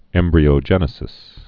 (ĕmbrē-ō-jĕnĭ-sĭs) also em·bry·og·e·ny (-ŏjə-nē)